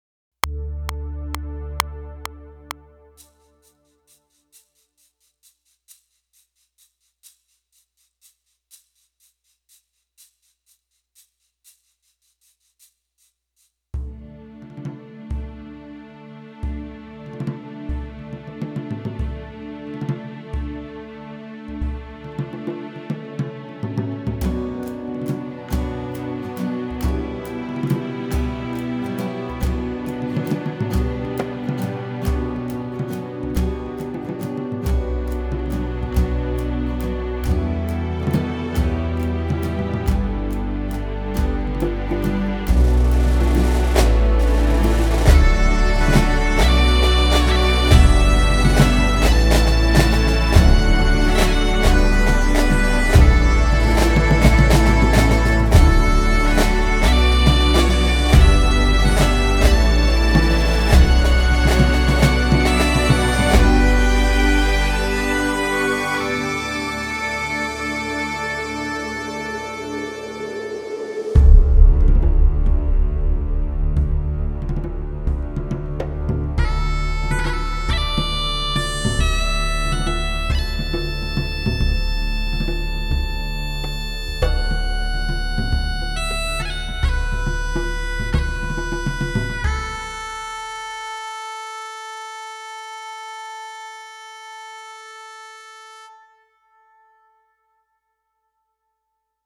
Télécharger Skye Boat Song (instrumental).mp3
skye-boat-song-instrumental.mp3